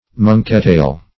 Search Result for " monkeytail" : The Collaborative International Dictionary of English v.0.48: Monkeytail \Mon"key*tail`\, n. (Naut.) A short, round iron bar or lever used in naval gunnery.